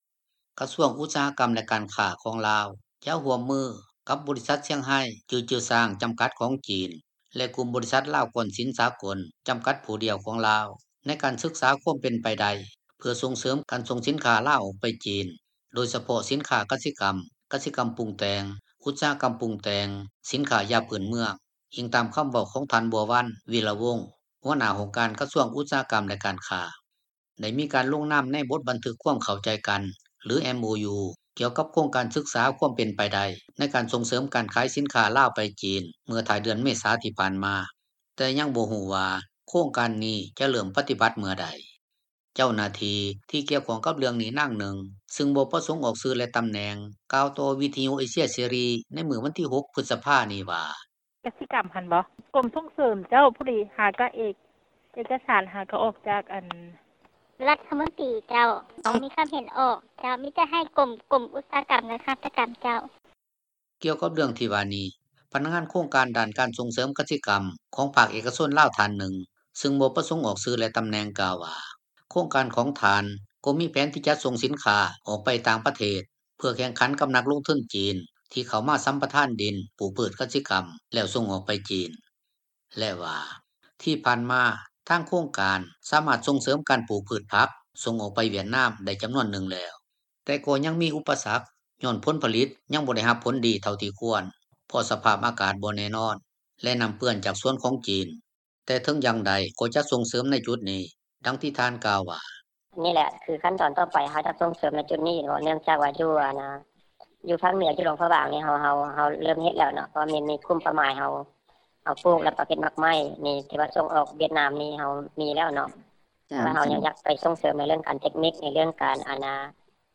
ເຈົ້າໜ້າທີ່ທີ່ກ່ຽວຂ້ອງກັບເລື່ອງນີ້ ນາງໜຶ່ງ ຊຶ່ງບໍ່ປະສົງອອກຊື່ ແລະ ຕຳແໜ່ງ ກ່າວຕໍ່ວິທຍຸເອເຊັຽເສຣີ ໃນມື້ວັນທີ 6 ພຶດສະພານີ້ວ່າ: